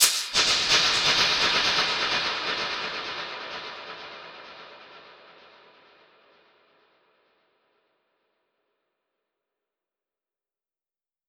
Index of /musicradar/dub-percussion-samples/85bpm
DPFX_PercHit_D_85-05.wav